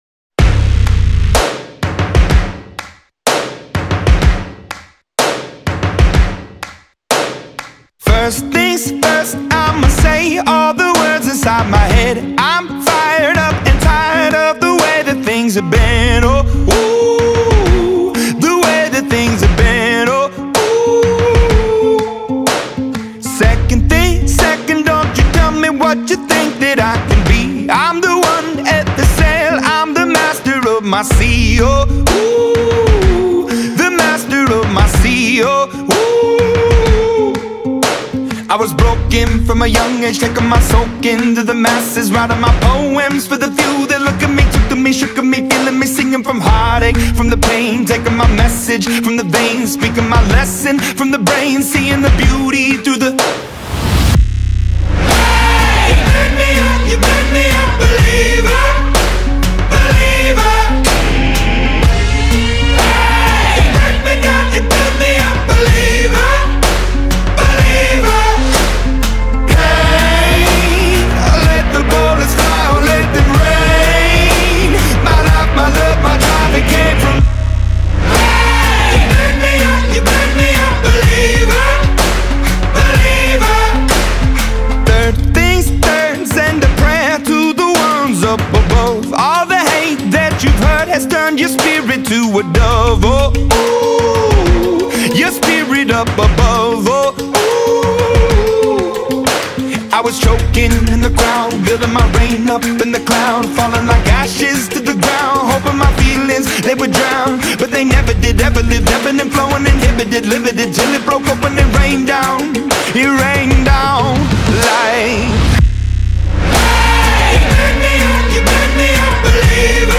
اهنگ انگيزشي خارجي